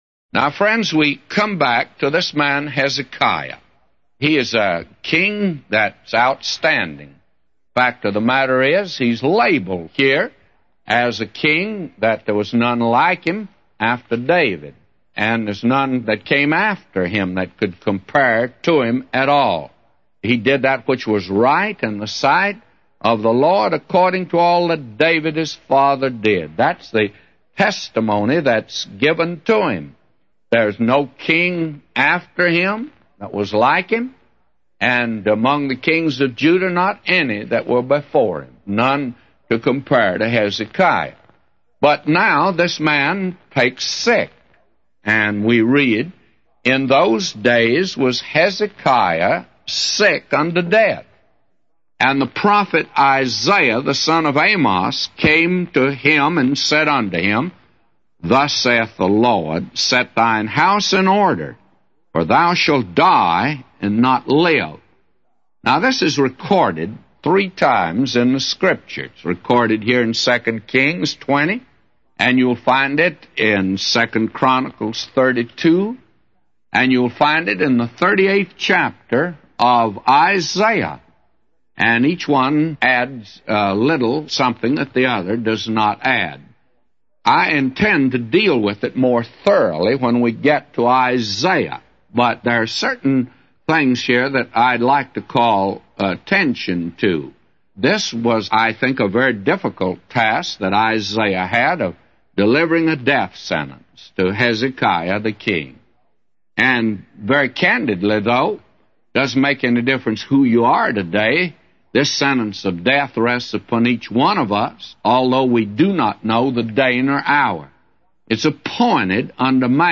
A Commentary By J Vernon MCgee For 2 Kings 20:1-999